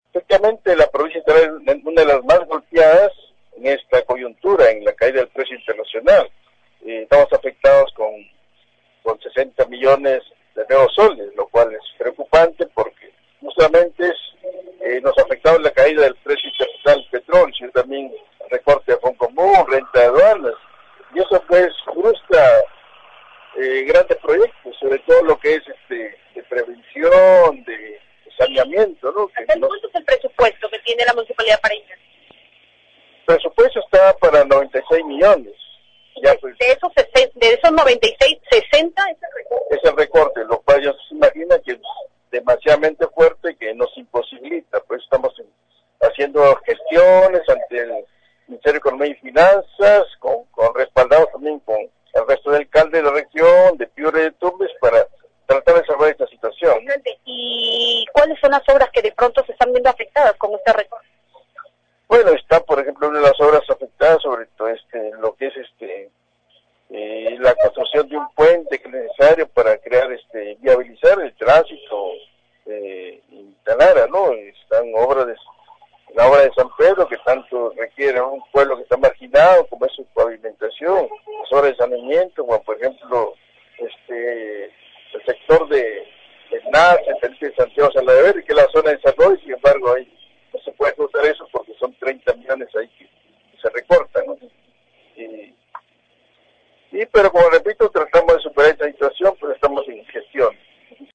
En diálogo con Cutivalú, Bolo Bancayán explicó que de los 96 millones de soles del presupuesto de inversión para este año, existe un recorte de 60 millones de soles que imposibilita la ejecución de obras.